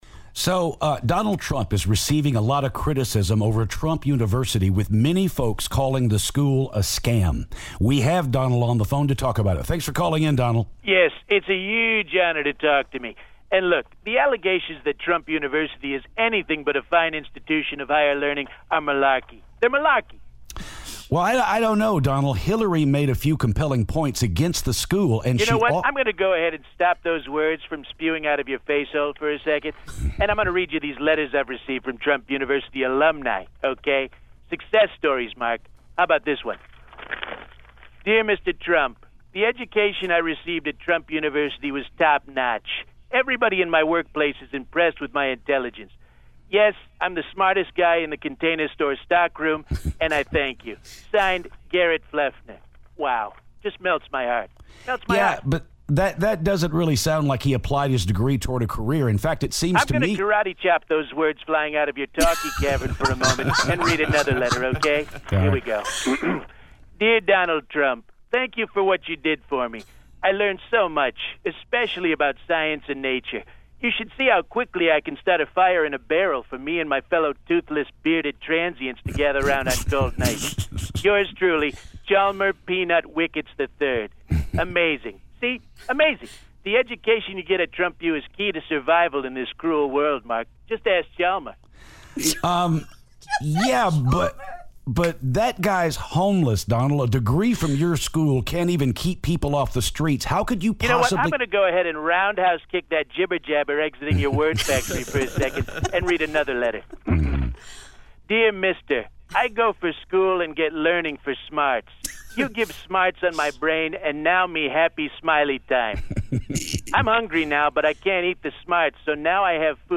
Donald Trump calls to talk about the controversy surrounding Trump University.